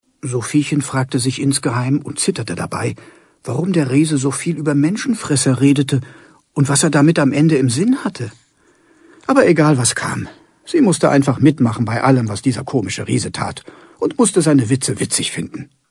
Deutscher Schauspieler, Synchronsprecher, Werbesprecher, Hörspielsprecher und Hörbuchinterpret.
H Ö R B E I S P I E L E – in der finalen Tonmischung: